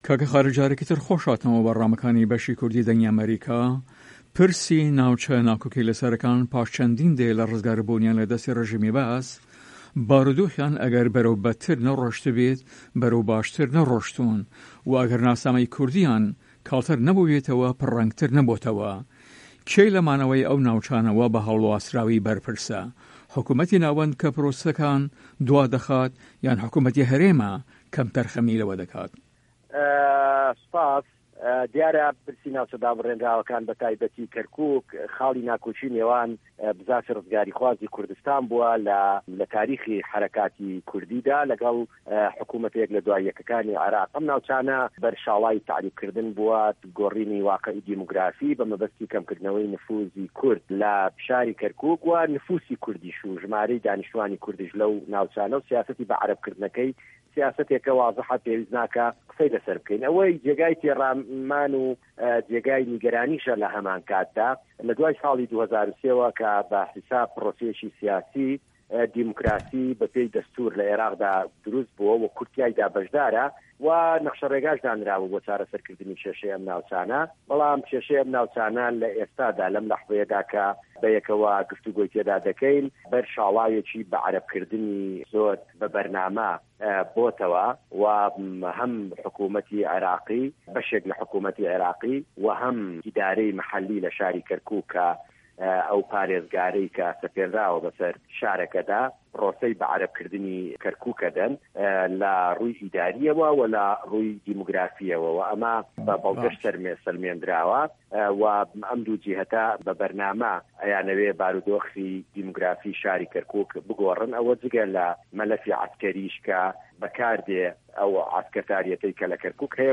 Interview with Khalid Shwani